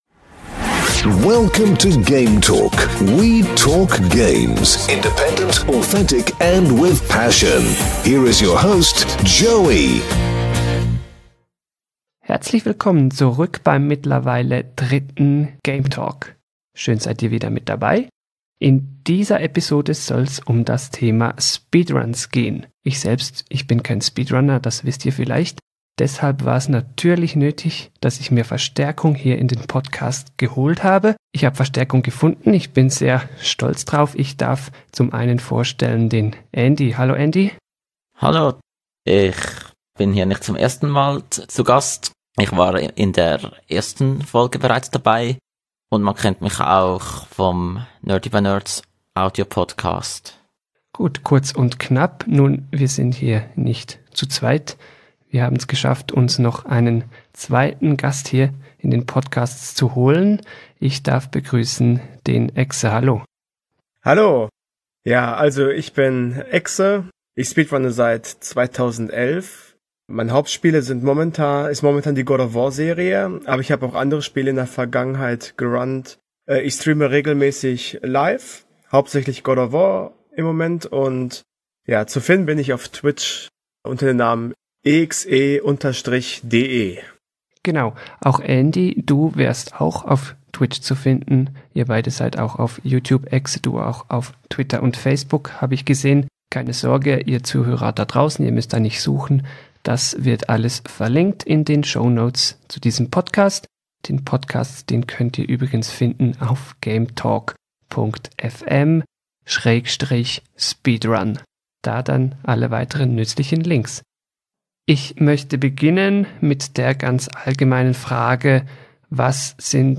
Subtitle Zwei Speedrunner sind zu Gast: Zusammen erzählen wir euch alles zum Thema Speedrunning.